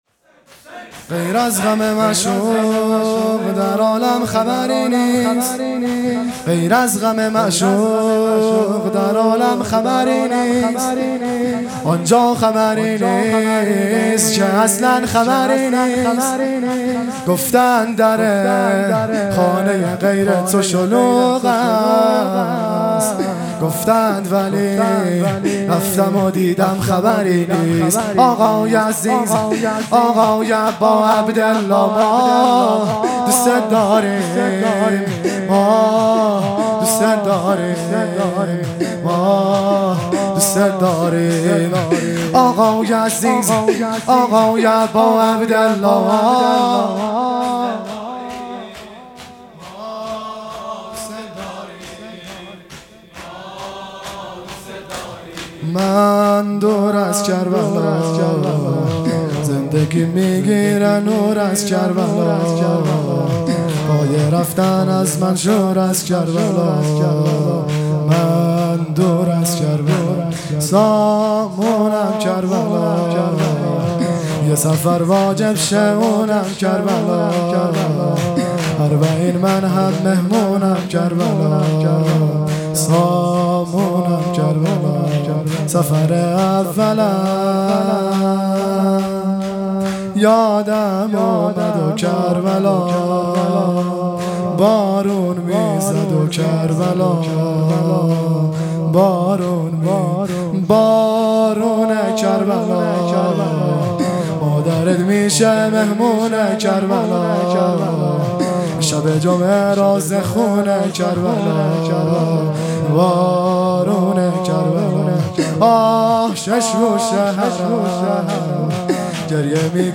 شور | غیر از غم معشوق در عالم خبری نیست | 1 اردیبهشت 1401
جلسۀ هفتگی | مناجات ماه رمضان | پنجشنبه 1 اردیبهشت 1401